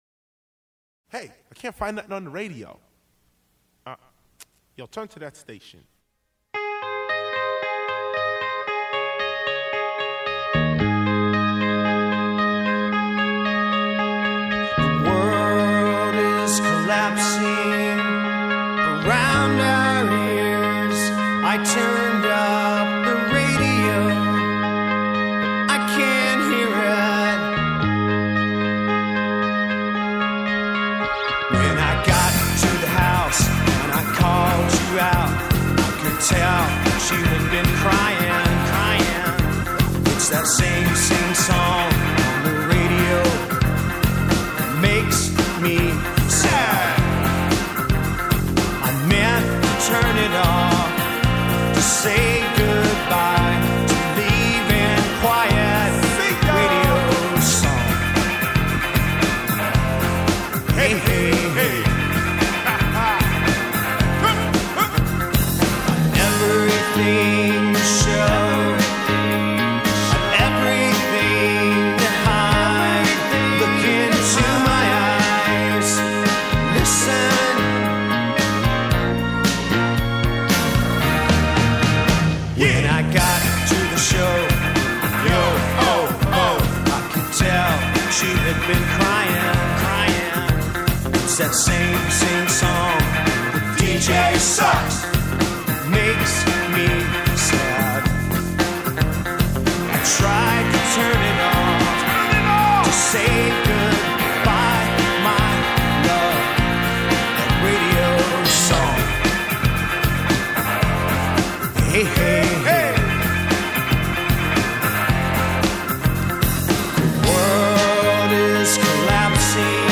Alternative Rock, Pop Rock